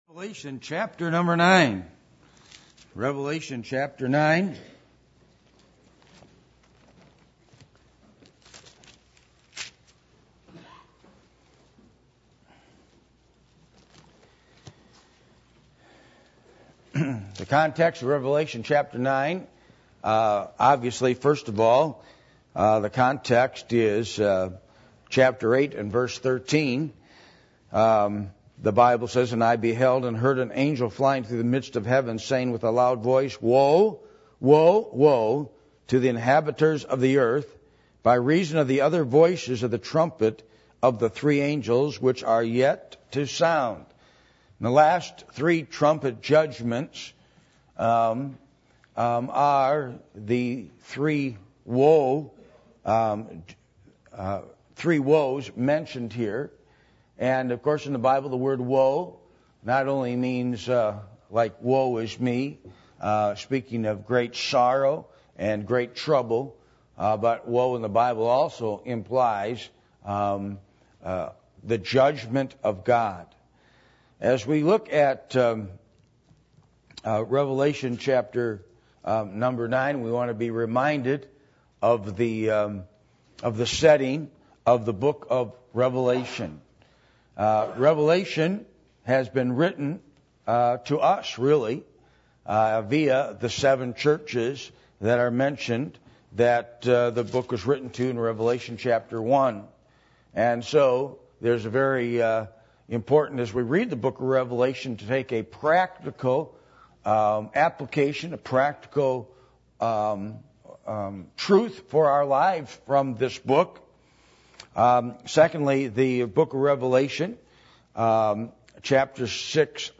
Passage: Revelation 9:1-21 Service Type: Sunday Morning